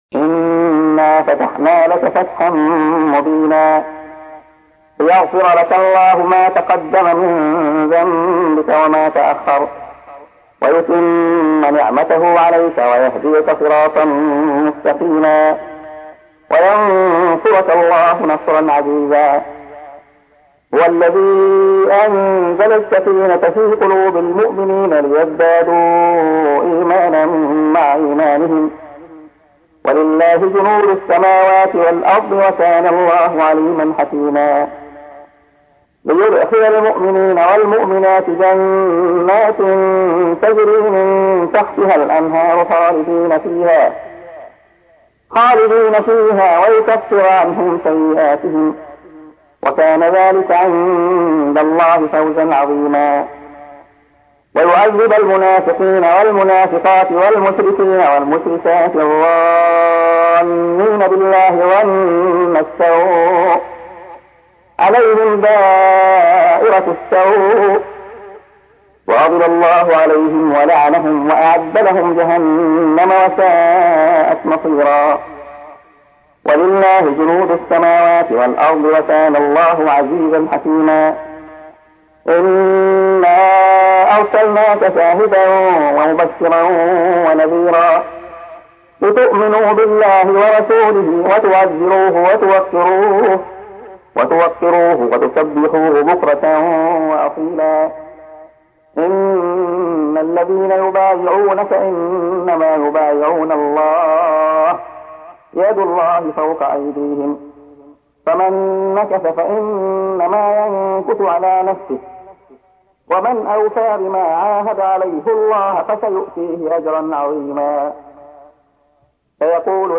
تحميل : 48. سورة الفتح / القارئ عبد الله خياط / القرآن الكريم / موقع يا حسين